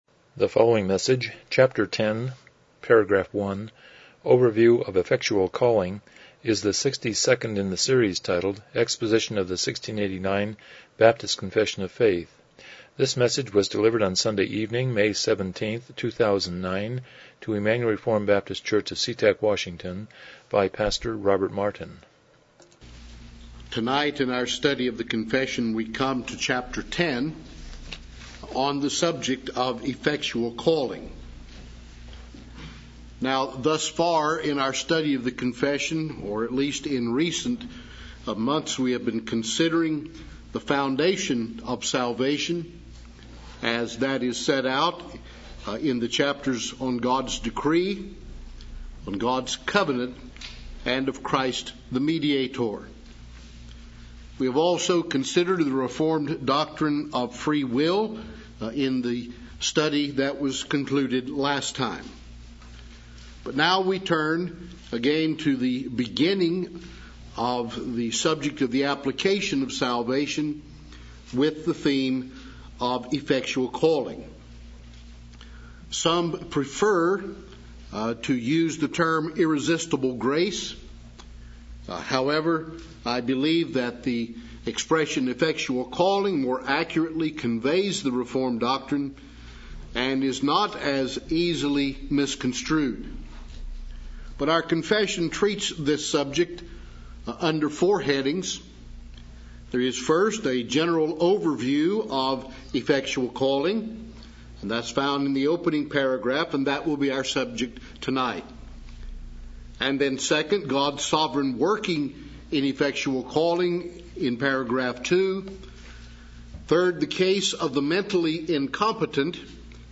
1689 Confession of Faith Service Type: Evening Worship « 80 Romans 6:23 33 The Abrahamic Covenant